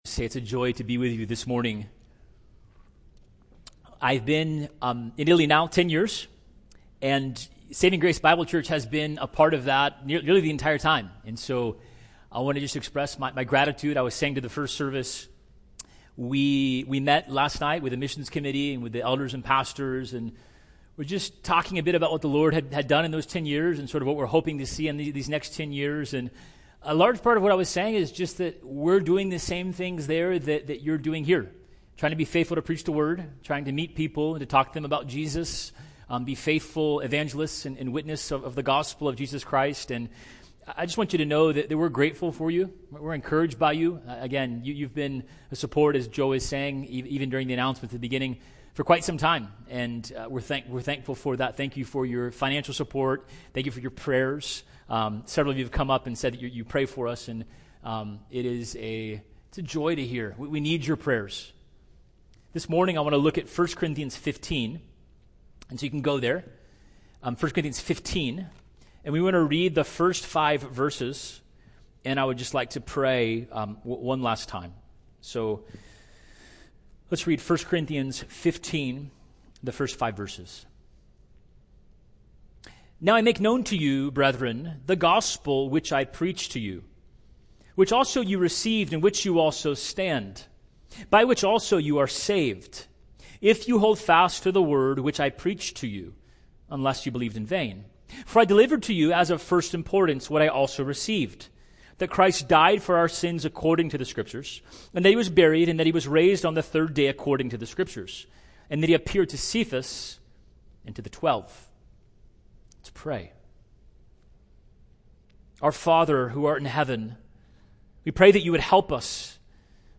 sermon-7-19-24.mp3